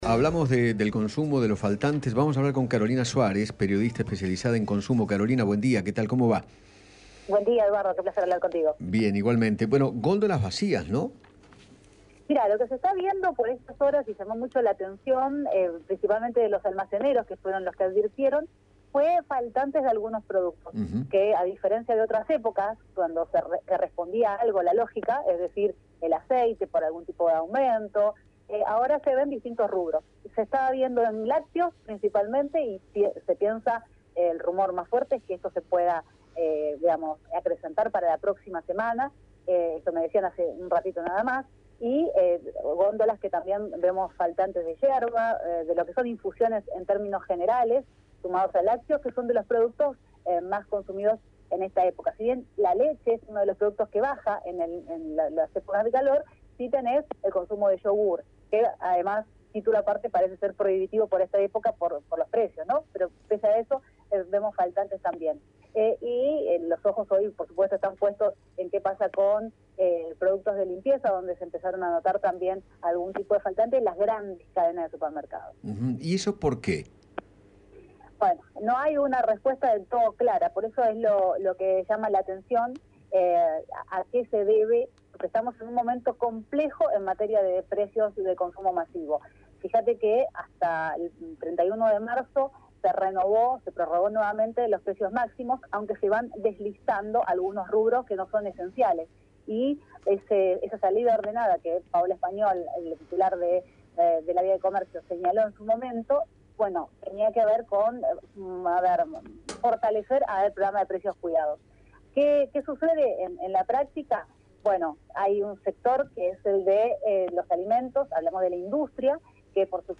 periodista especialista en consumo